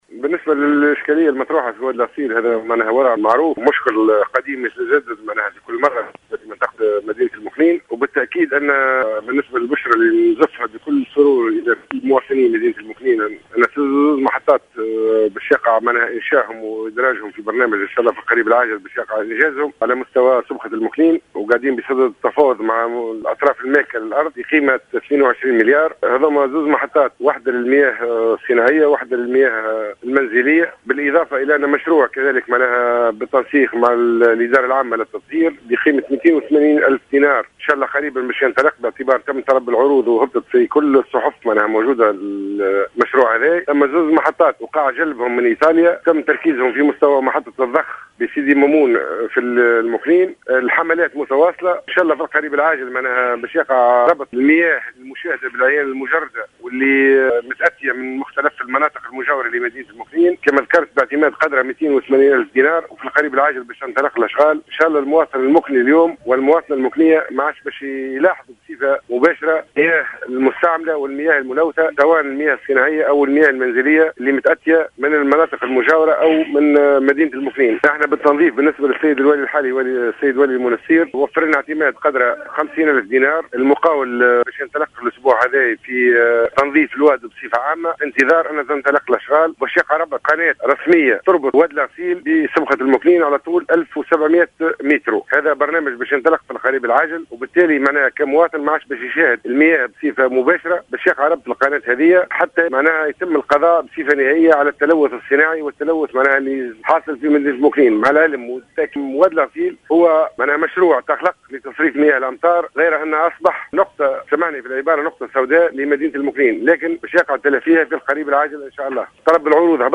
أكد حسين بوشهوة المعتمد الأول السابق بولاية المنستير في تصريح للجوهرة أف أم اليوم السبت 19 سبتمبر 2015 أنه سيقع انجاز محطتين للتطهير على مستوى سبخة المكنين من ولاية المنستير لحل مشكل التلوث في وادي الغسيل بالجهة.